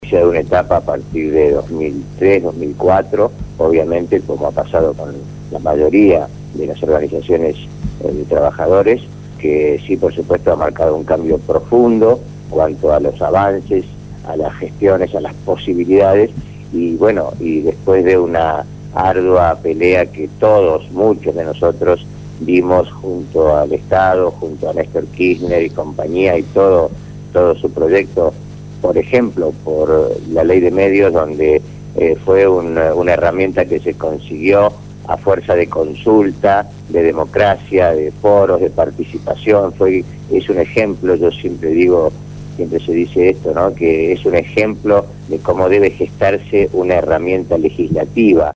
La  entrevista fue realizada en el programa Punto de Partida de Radio Gráfica FM 89.3